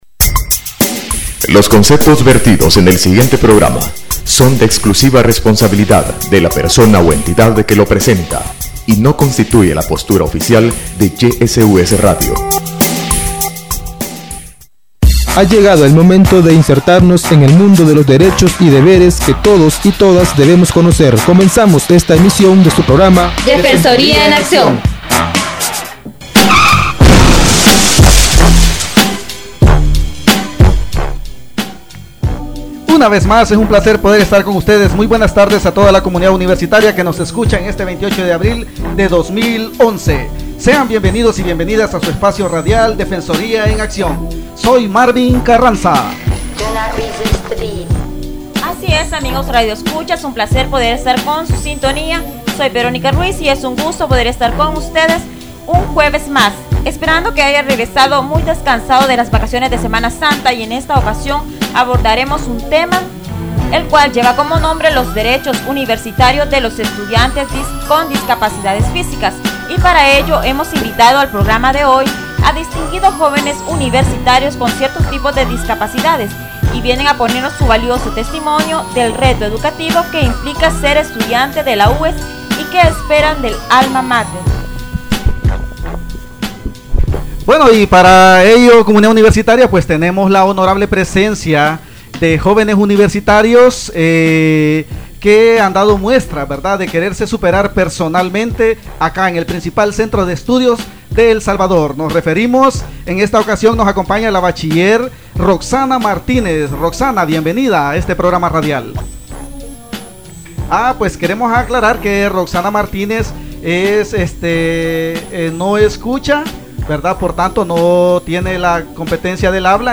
Análisis de las condiciones educativas de estudiantes con capacidades especiales matriculados en la Universidad de El Salvador. Entrevista a estudinates no videntes, sordomudos y con condicion especial para desplazarse.